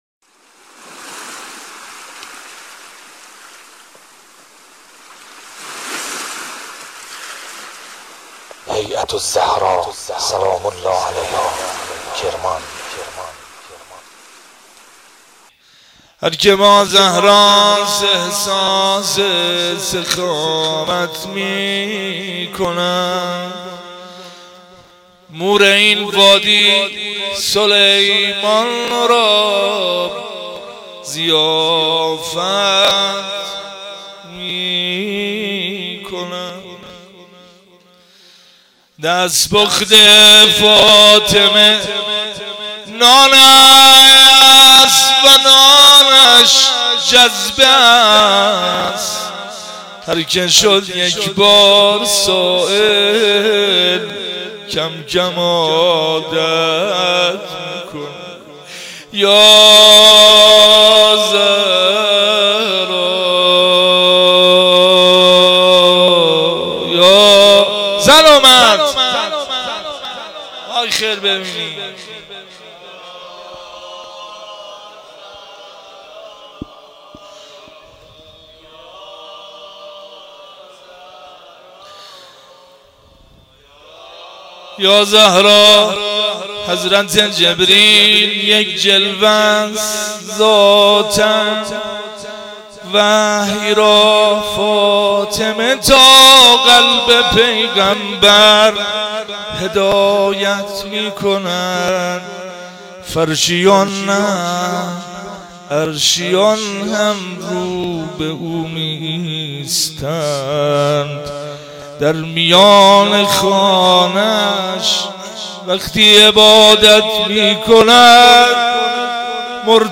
روضه حضرت زهرا(س)